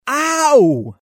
Ow Téléchargement d'Effet Sonore
Ow Bouton sonore